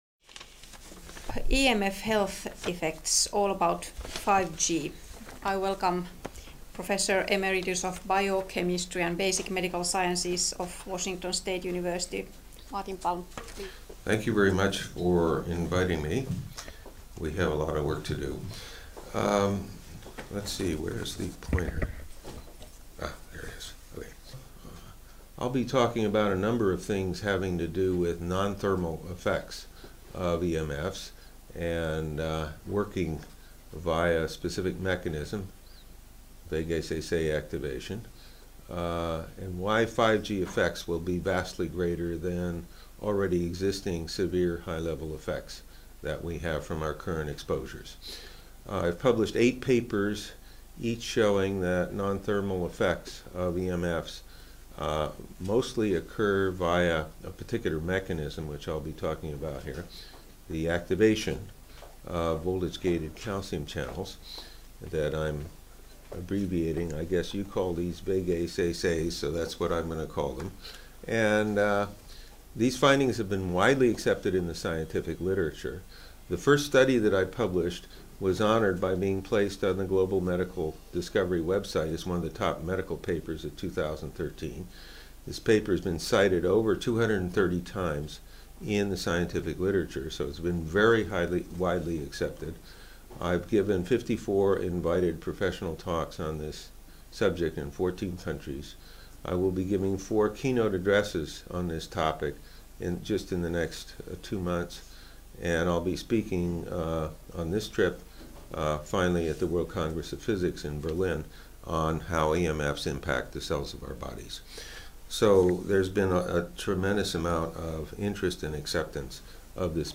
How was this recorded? "Viisi gee" seminaari Helsingissä 2019 - Osa 4